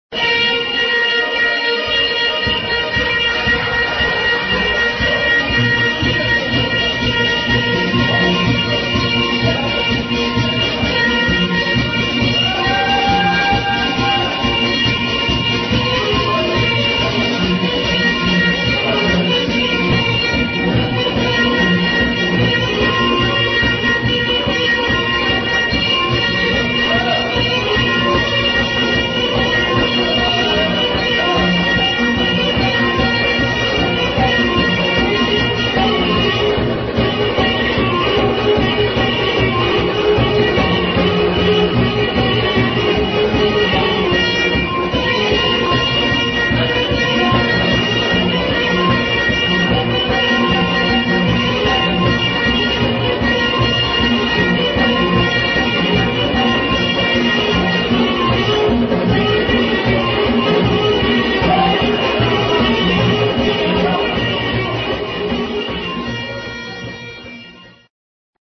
A high speed dance that is also danced by Kurds, Armenians and Iranians